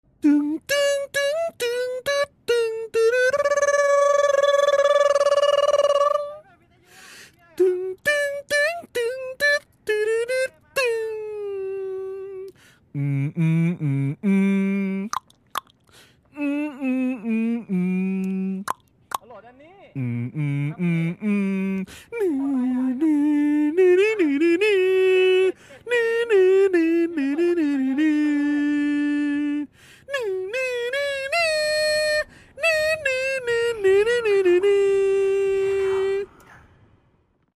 เสียงเรียกเข้าติ๊กต๊อก